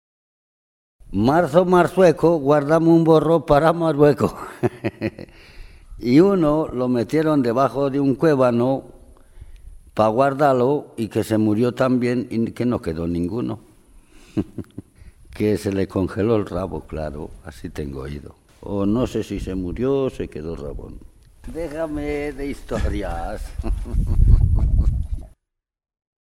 Clasificación: Cuentos
Lugar y fecha de grabación: San Vicente de Robres, 21 de agosto de 2006